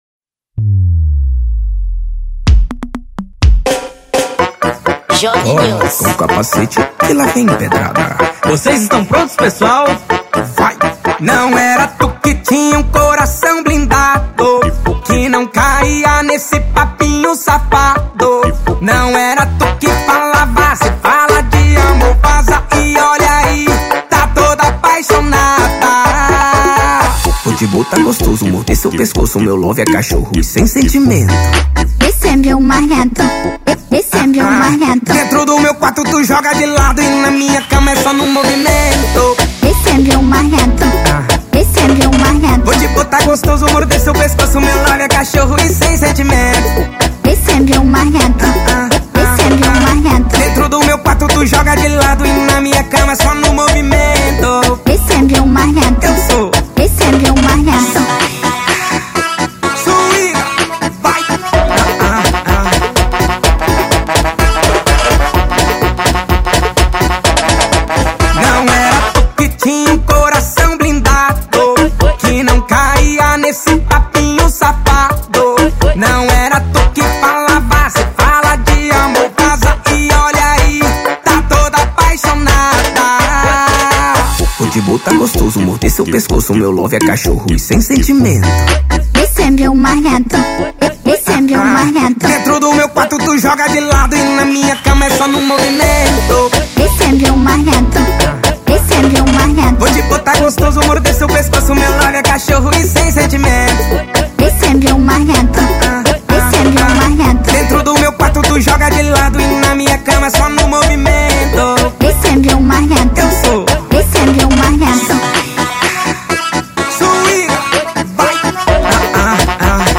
Gênero: Pagode